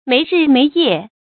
沒日沒夜 注音： ㄇㄟˊ ㄖㄧˋ ㄇㄟˊ ㄧㄜˋ 讀音讀法： 意思解釋： 猶言不分白天夜晚。